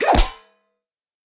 Player Clunk Hit Sound Effect
Download a high-quality player clunk hit sound effect.
player-clunk-hit.mp3